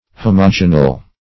Homogeneal \Ho`mo*ge"ne*al\, a.
homogeneal.mp3